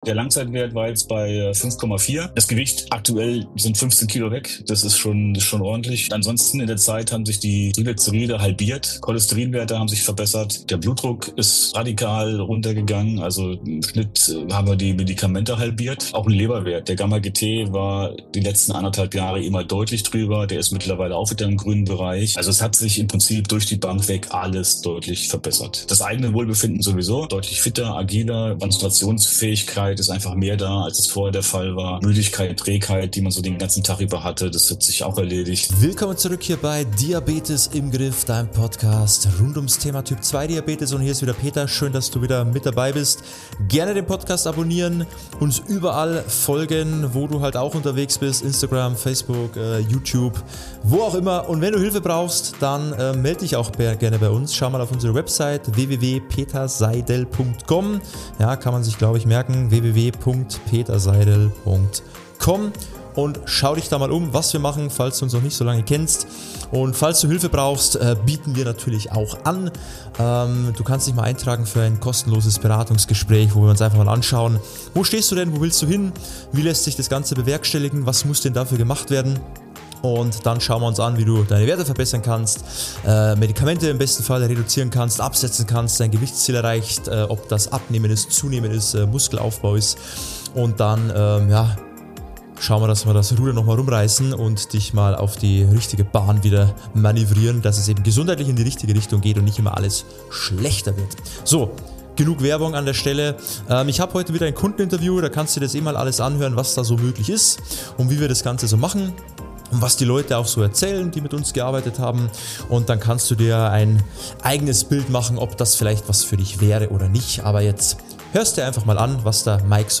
Ein ehrlicher Erfahrungsbericht über nachhaltige Veränderung bei Typ-2 Diabetes.